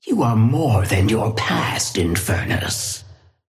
Sapphire Flame voice line - You are more than your past, Infernus.
Patron_female_ally_inferno_start_01.mp3